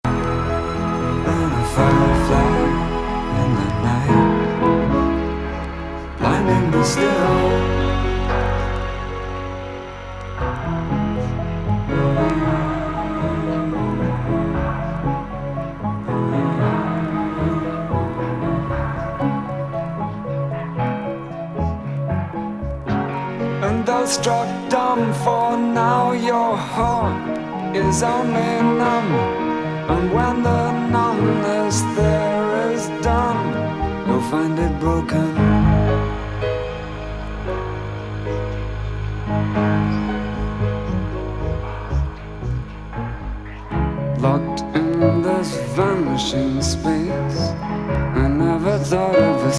スペース・レフティ・グルーヴ!
Rock / Pops 80's